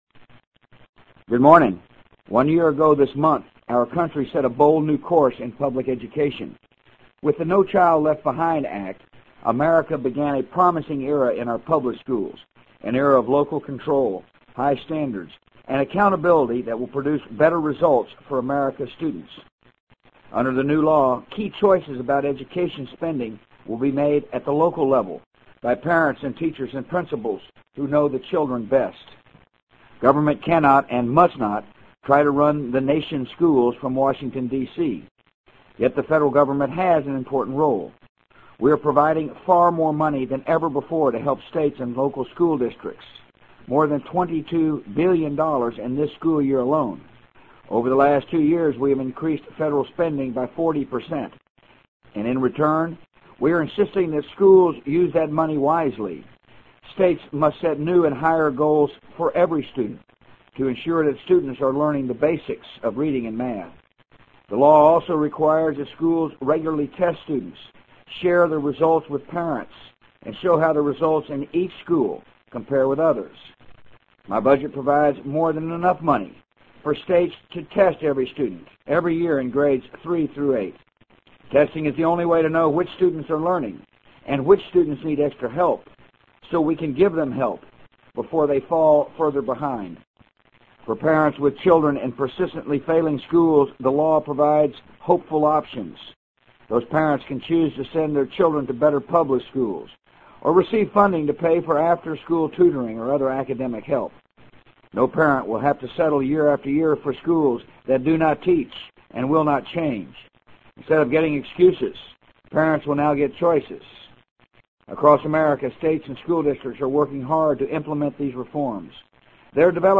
【美国总统George W. Bush电台演讲】2003-01-04 听力文件下载—在线英语听力室